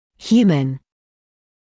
[‘hjuːmən][‘хйуːмэн]человеческий; человек